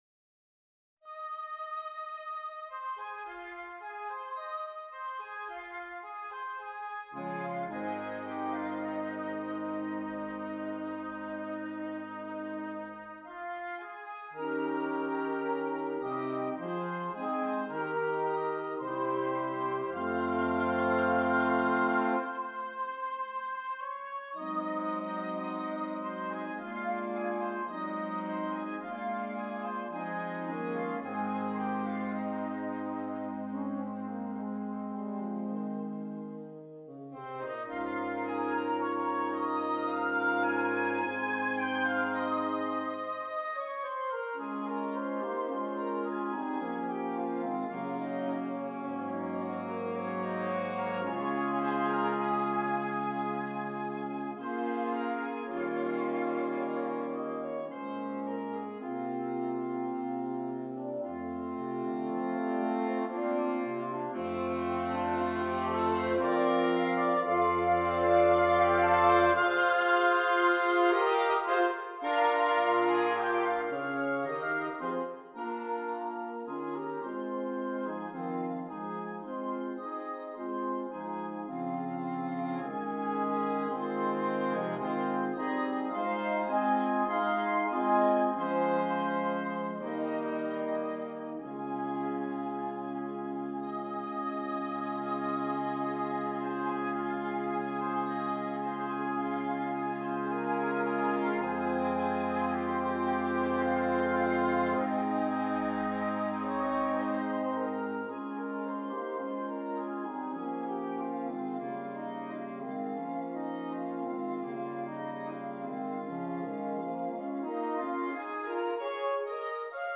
Interchangeable Woodwind Ensemble
with its impressionistic and progressive harmonies